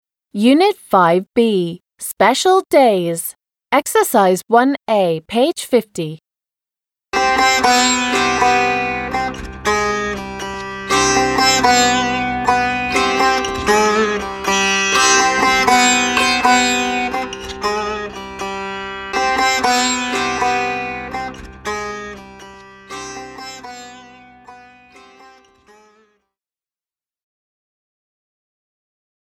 Ответ: I think this music is from India − Я думаю, эта музыка из Индии.